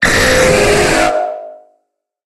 Cri de Méga-Dracolosse dans Pokémon HOME.
Cri_0149_Méga_HOME.ogg